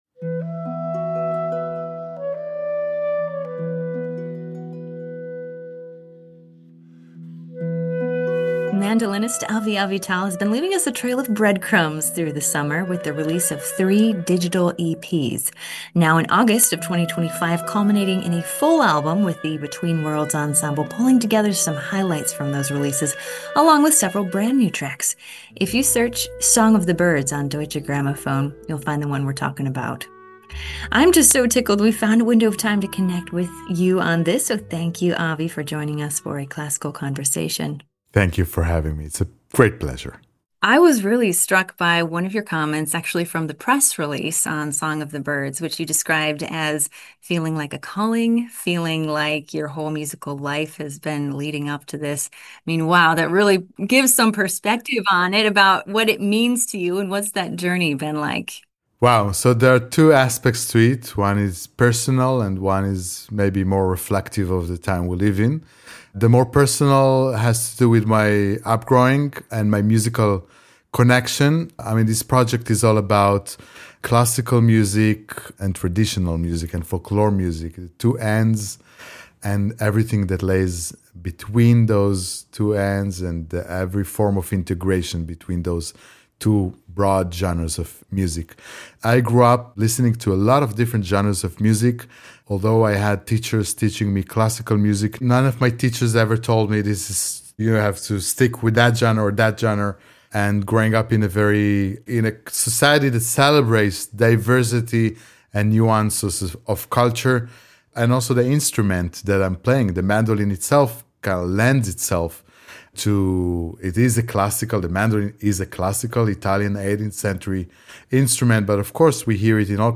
Classical-Conversation-with-Avi-Avital-Session.mp3